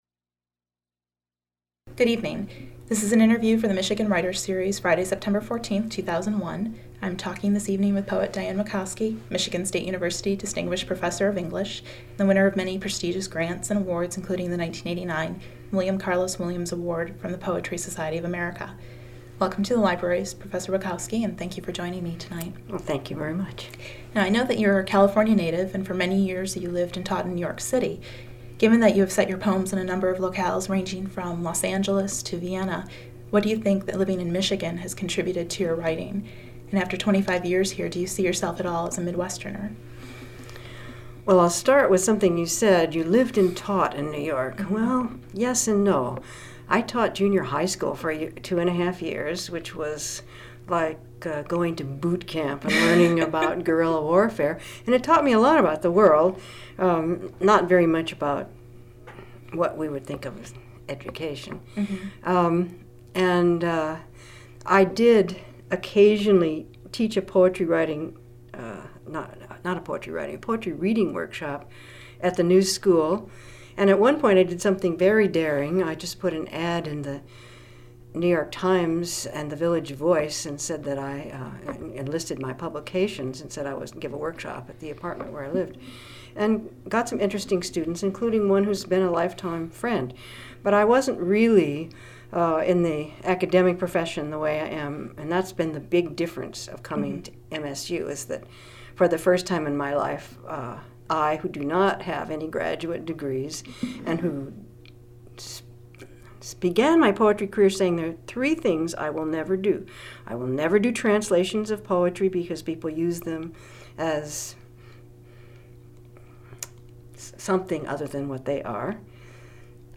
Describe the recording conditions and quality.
Interviewed at the Michigan State University Libraries and recorded by the Vincent Voice Library on Sept. 14, 2001.